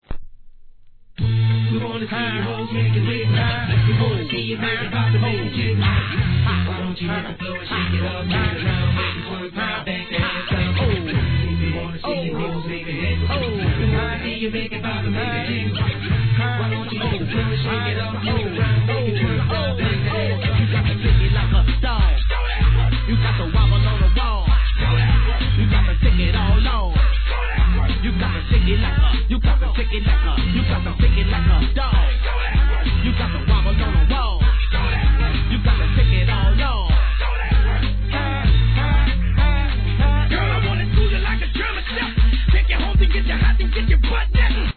G-RAP/WEST COAST/SOUTH
ド迫力の戦慄ビートに怒涛のマイクリレーがハンパない激バウンスチューン！！